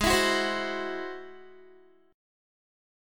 Listen to G#+7 strummed